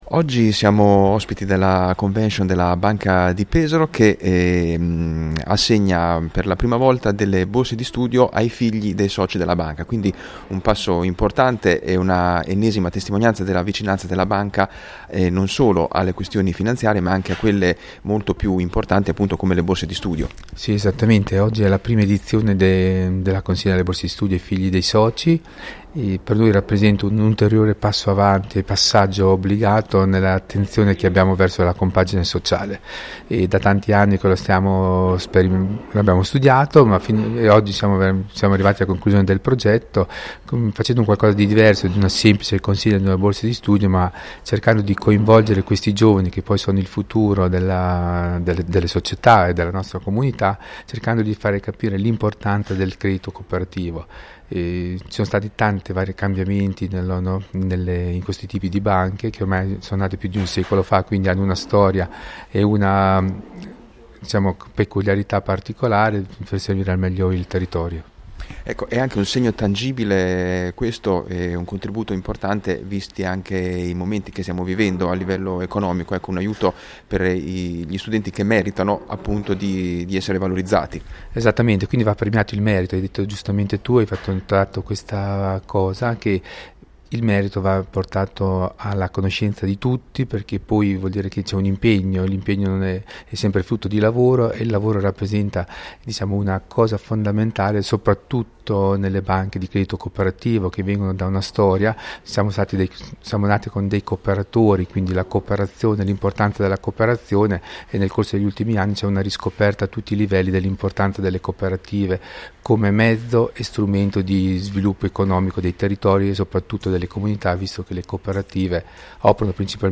La Banca di Pesaro Credito Cooperativo è lieta di annunciare la prima edizione delle borse di studio dedicate ai familiari dei Soci, un’iniziativa che mira a sostenere il percorso formativo e accademico delle nuove generazioni e rappresenta un momento importante per sottolineare l’impegno della banca nei confronti della comunità e per promuovere valori di sostegno e vicinanza alle famiglie. Ai nostri microfoni: